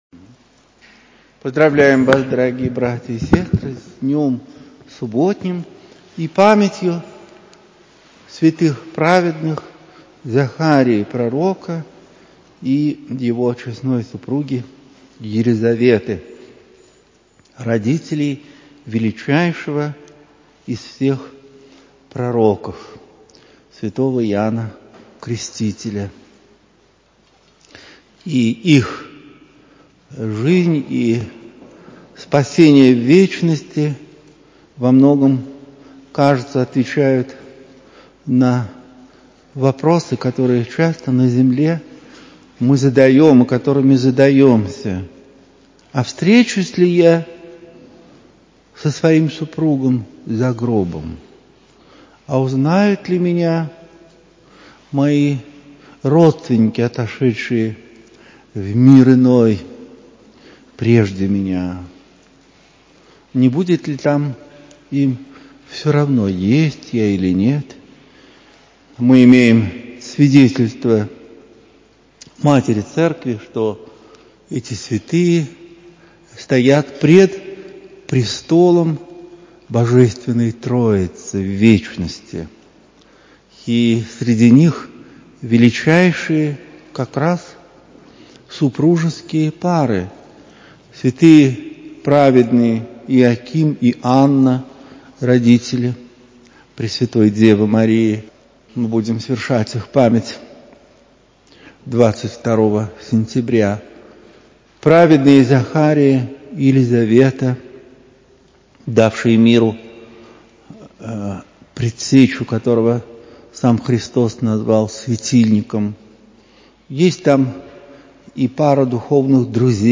В храме Всех Святых Алексеевского ставропигиального женского монастыря, 17 сентября 2021.